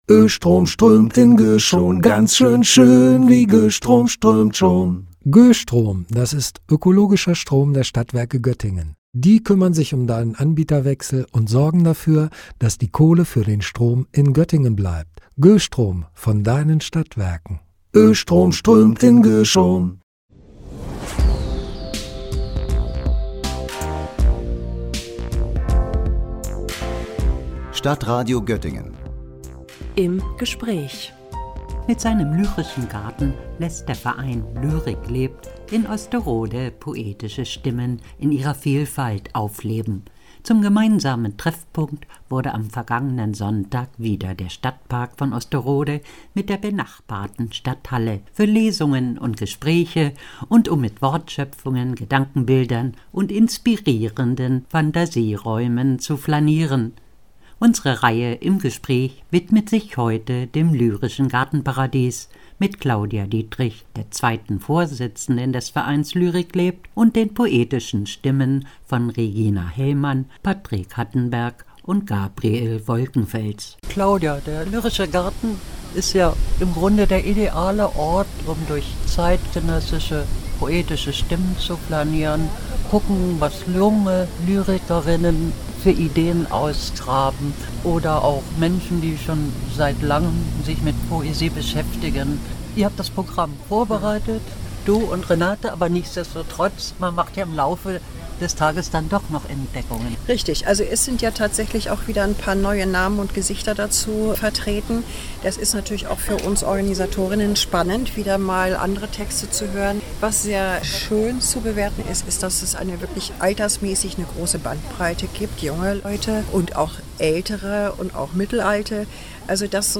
Der lyrische Garten in Osterode – ein Gespräch über poetische Stimmen und Motive